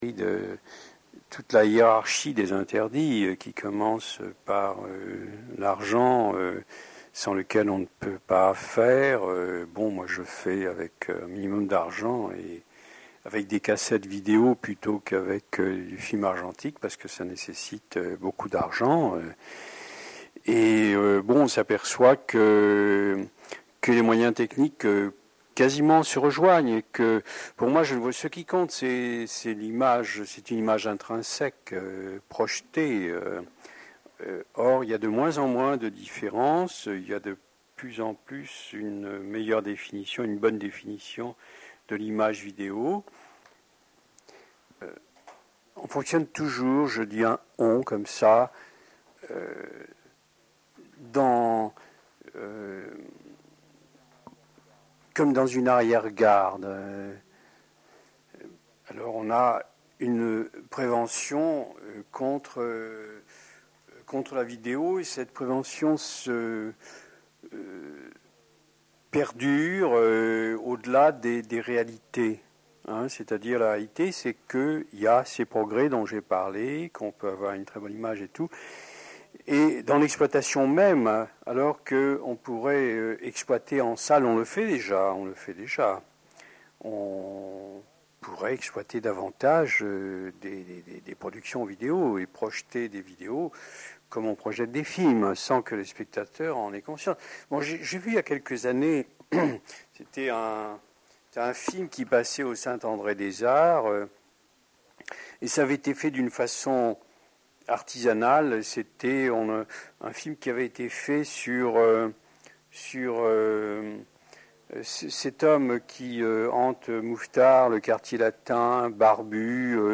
Hommage : entretiens avec Marcel Hanoun (1ere partie)